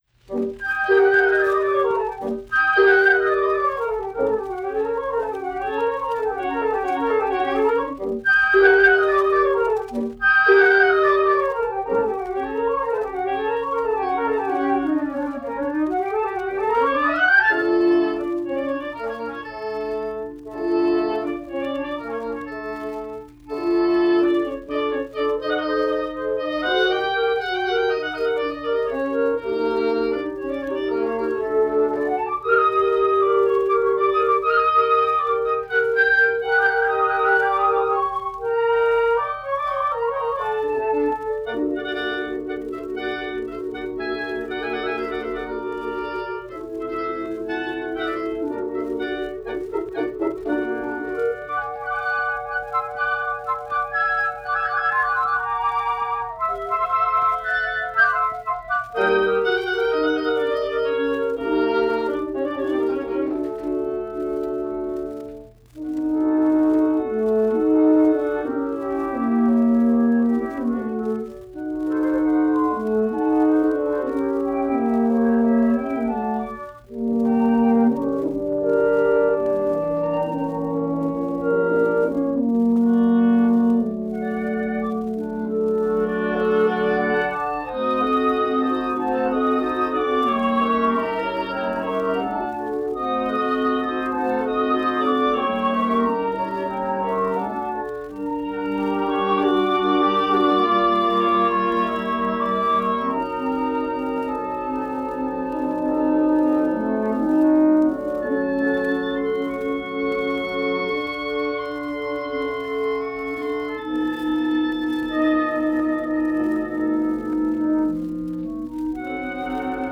The following pieces were performed at the Curtis Institute of Music by various wind ensembles from 1936 to 1941 under the direction of Marcel Tabuteau.
Format: 78 RPM